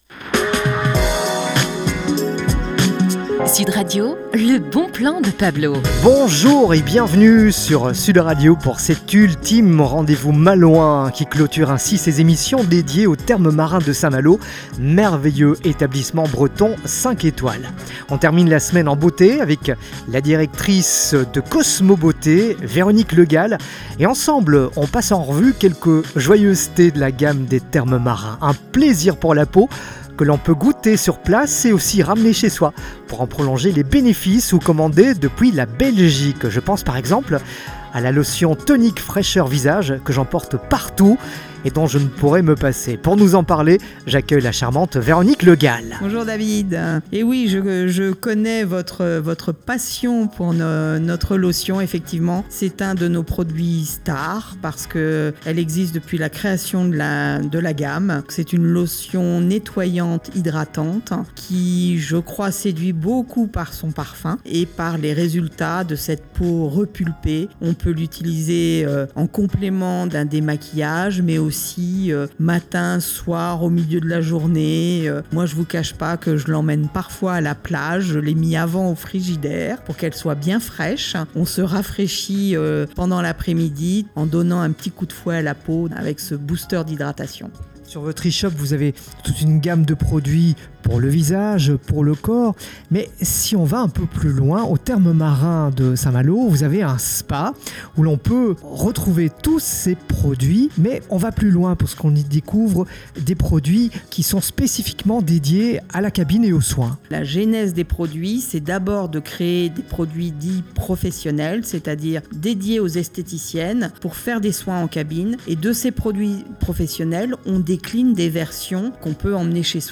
Interview Sud Radio